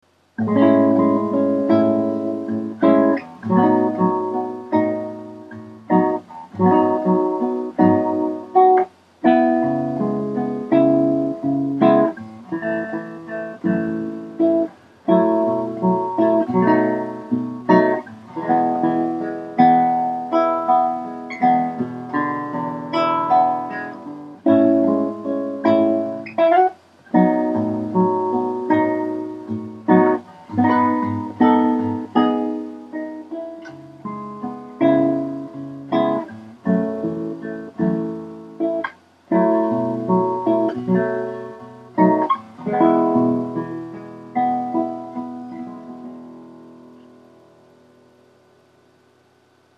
■ クラシックギター（ピックアップマイク単体）
6. ピエゾマイクのみのサウンド
弦の音が特に強調されるので、弦の芯音を捉えたい場合にはおすすめです。